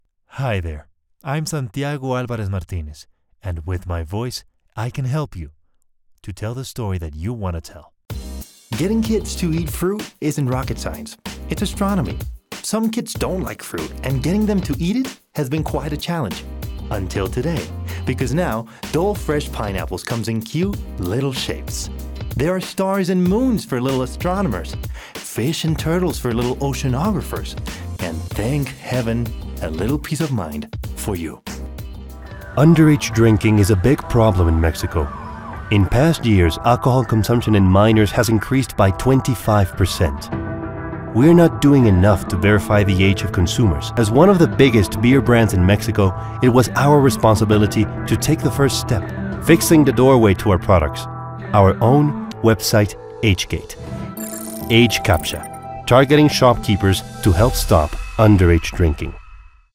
Commercial Demos
Commercial-Demo-English-May-2024_mezcla.mp3